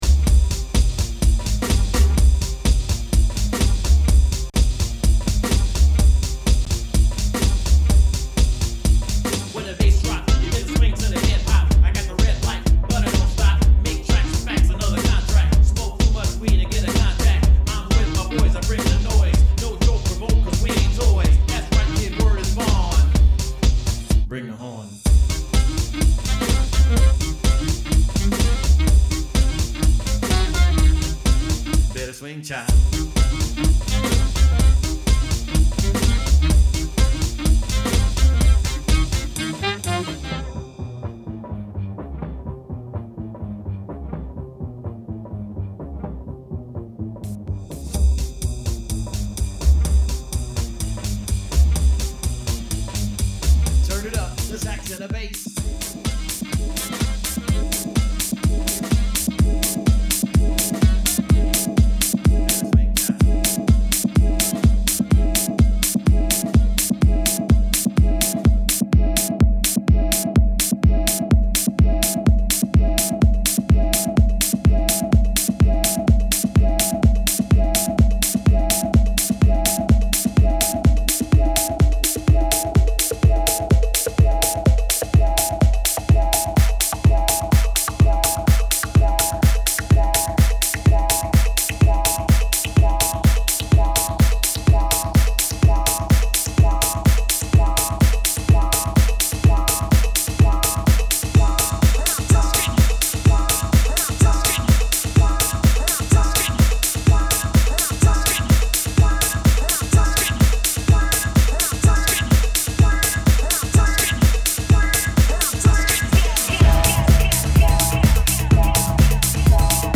Here is one of my parts from Nov 5th 2011 at the new Gusto Lounge.